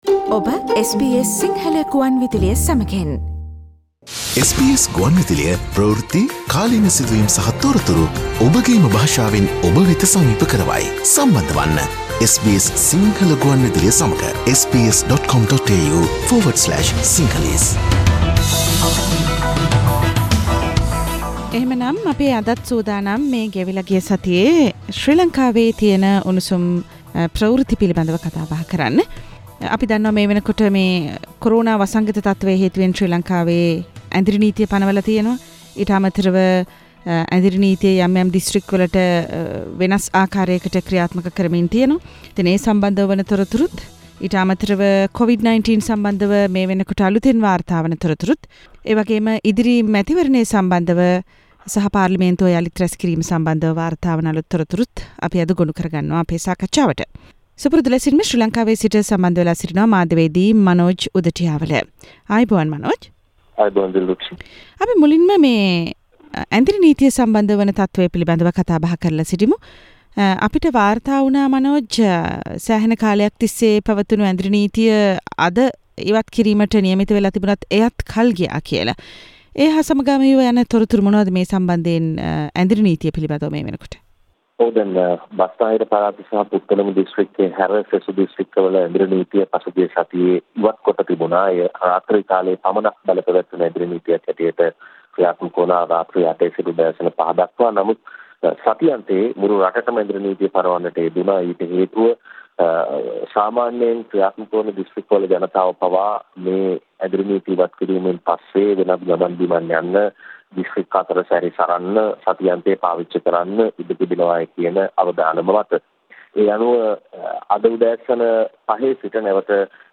Sri Lankan news wrap Source: SBS Sinhala radio